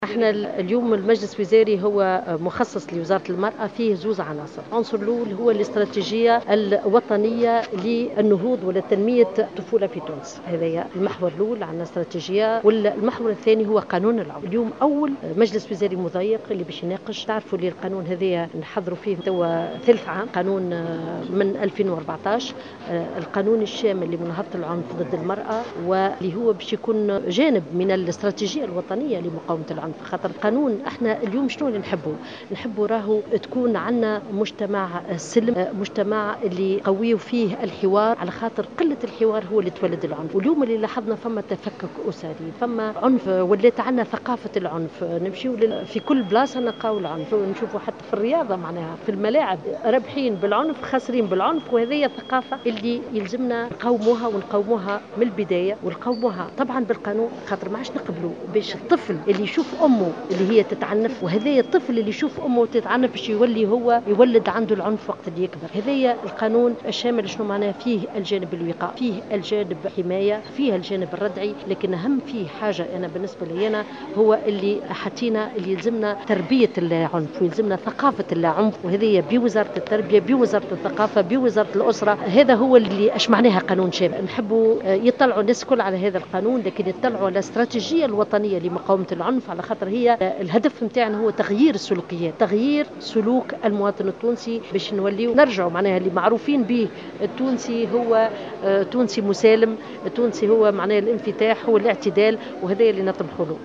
أكدت وزيرة المرأة سميرة مرعي في تصريح للجوهرة أف أم أنه سينعقد اليوم الجمعة 22 أفريل 2016 أول مجلس وزاري مضيق سيناقش القانون الشامل لمناهضة العنف ضد المرأة.